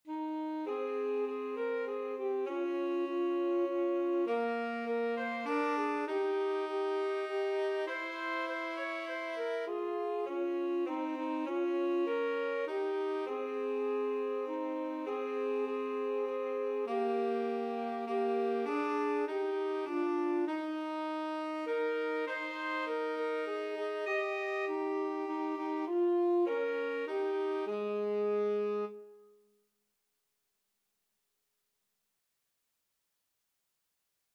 Alto SaxophoneTenor Saxophone
Moderato
3/4 (View more 3/4 Music)